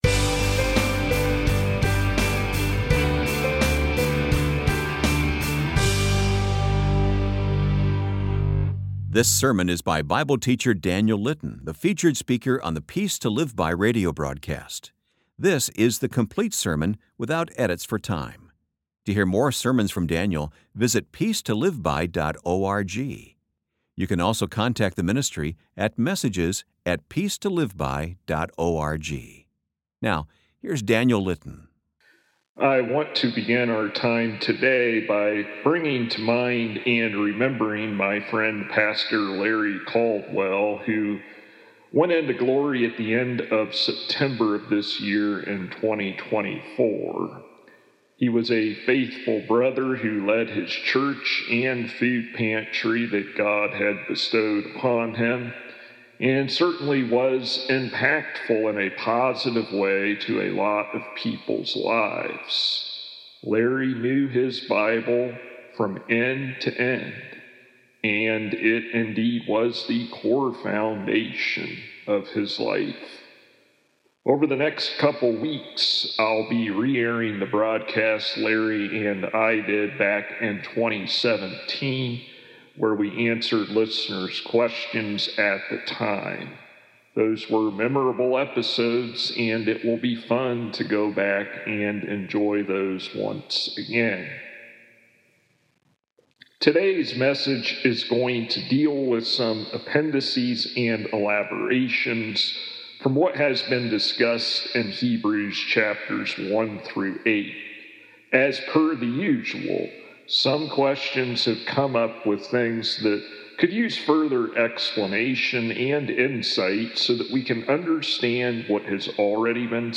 Meditations on Hebrews Full Sermons
Meditations on Hebrews: Intro & Chapter 1, Part 1 Tap to play full sermon without edits for time.